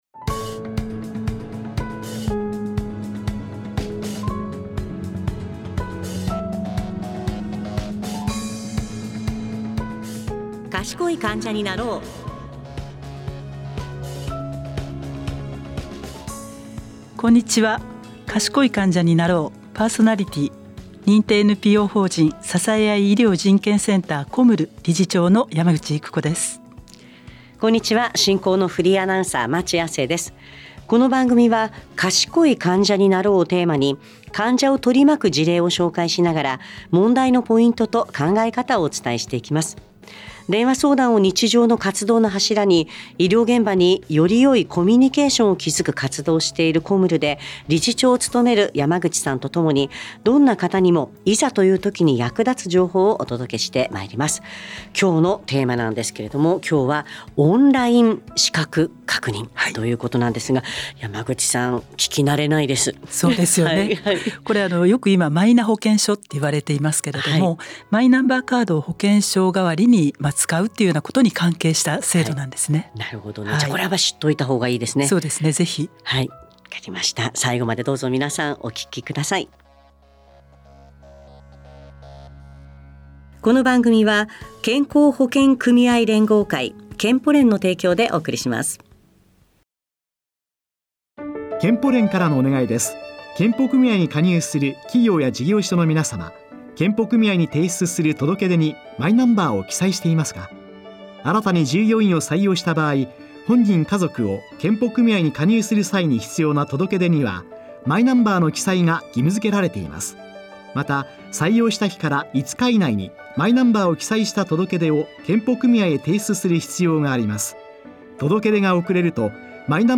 - 第10回 「オンライン資格確認」 by ラジオNIKKEI on Podcast Addict. 4月26日の放送では、「オンライン資格確認」をテーマにお話いただきました。